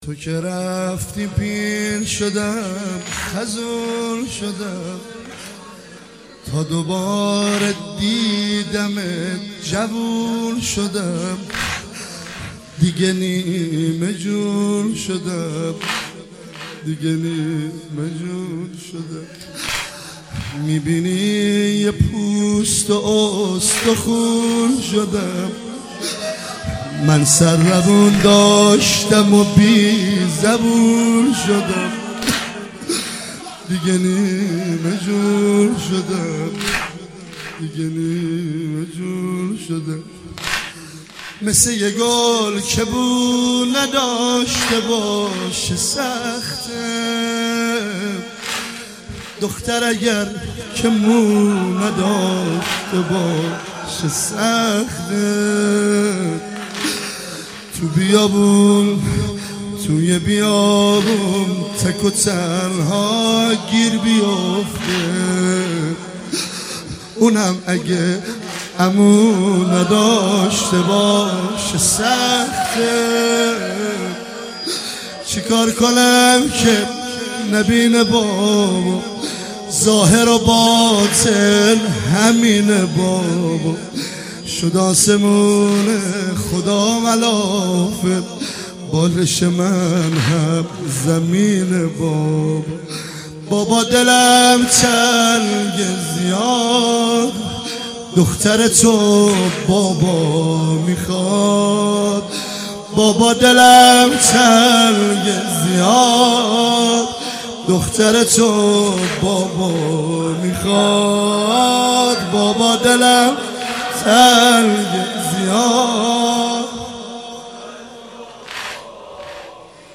مداحی شب سوم محرم 99